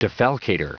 Prononciation du mot defalcator en anglais (fichier audio)
Prononciation du mot : defalcator